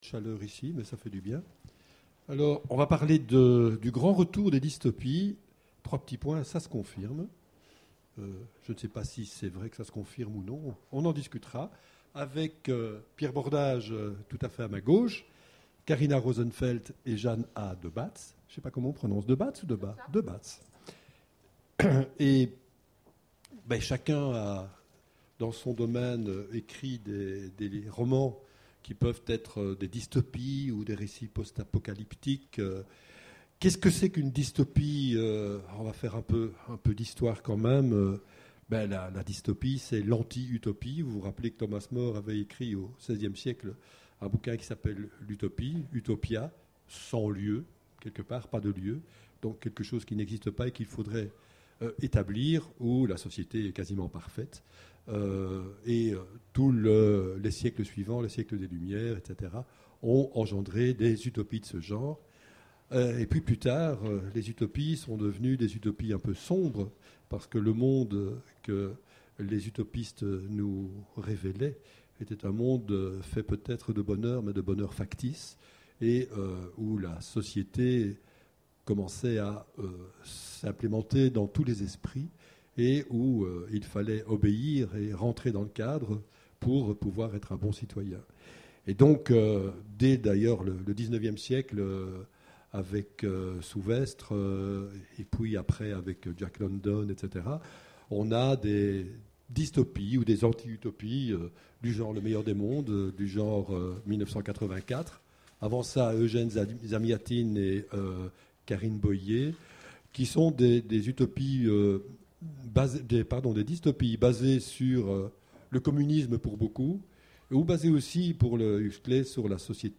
Imaginales 2014 : Conférence Le grand retour des dystopies